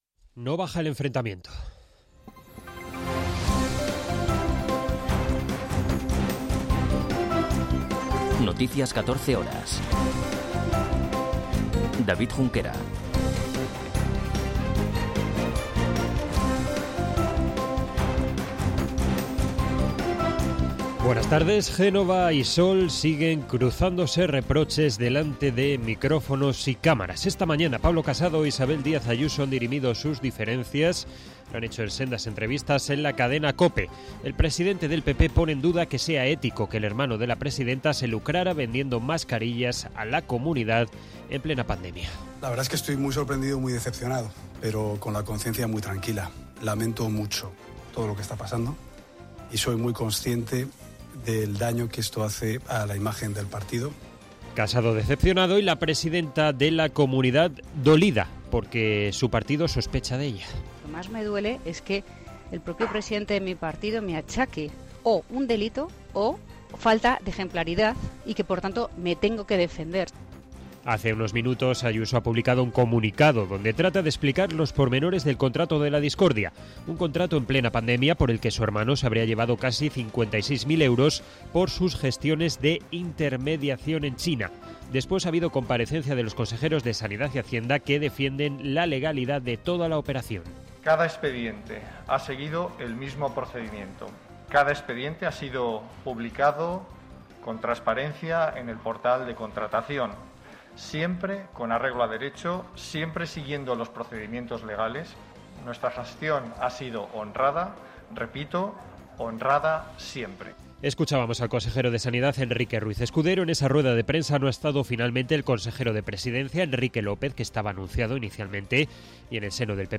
Noticias 14 horas 18.02.2022